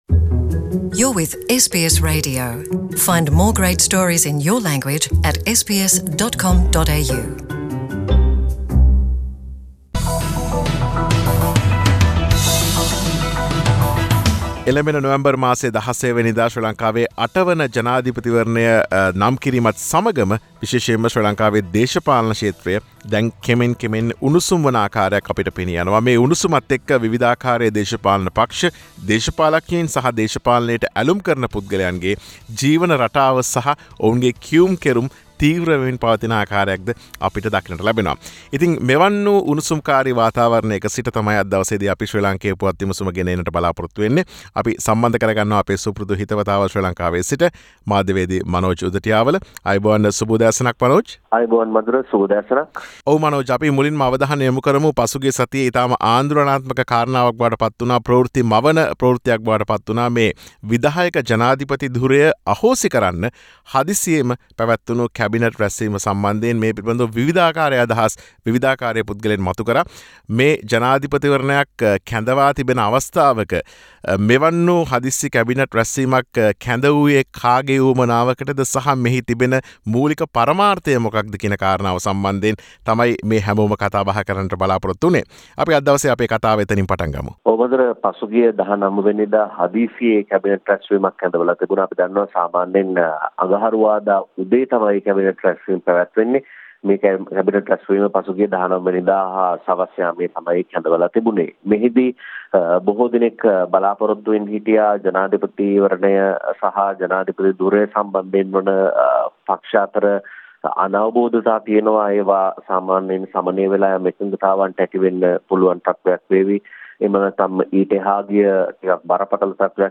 සතියේ ශ්‍රී ලාංකේය දේශපාලන පුවත් සමාලෝචනය.